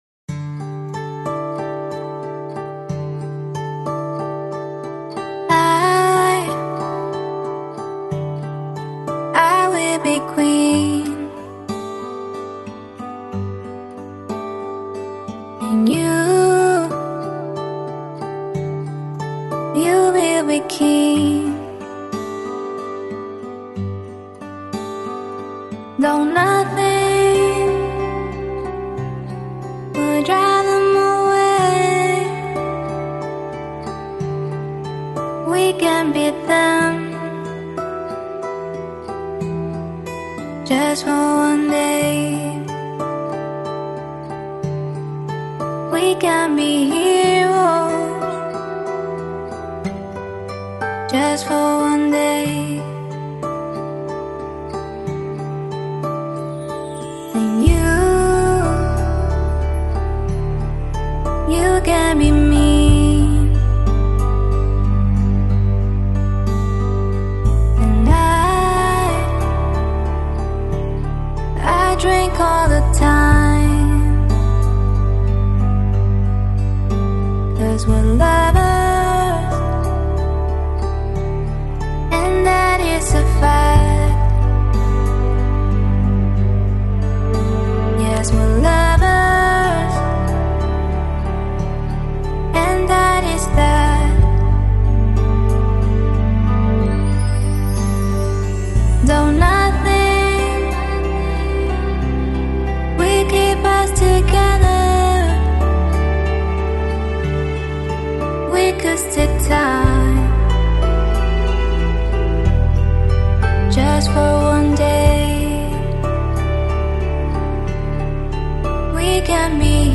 Жанр: Lounge, Downtempo, Jazz, Pop